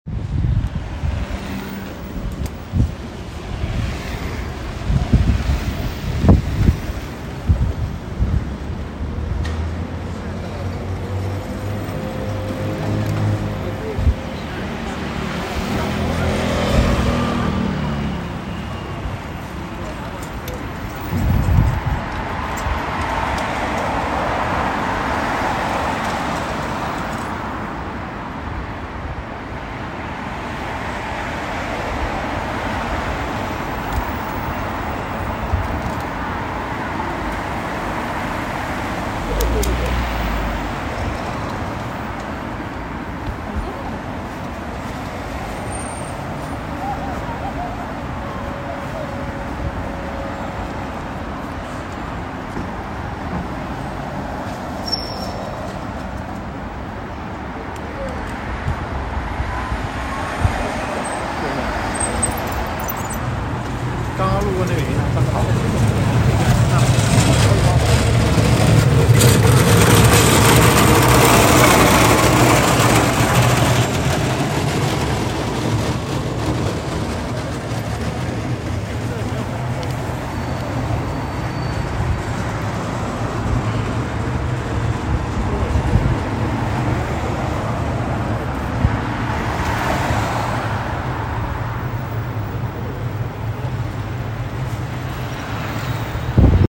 Sounds from home (elsewhere) are overlaid and geo-located along the canal.